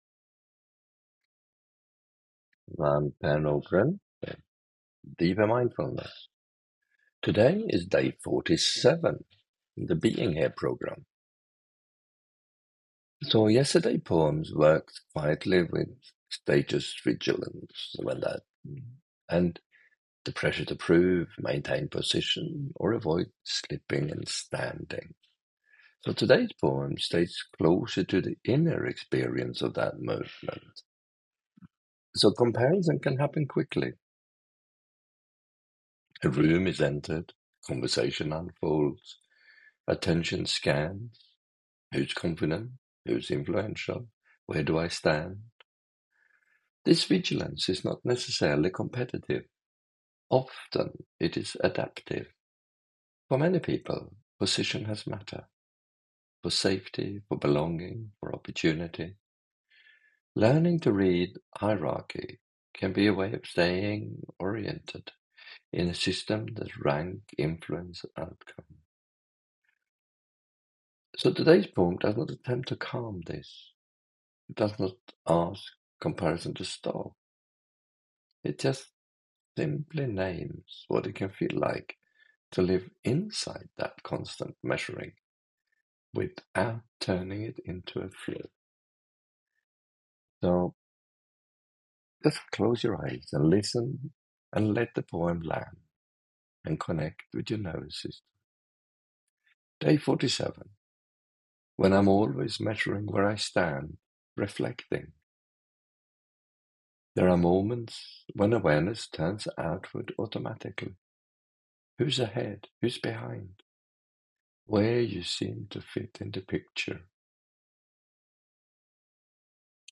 Poem – When I’m always measuring where I stand – Reflecting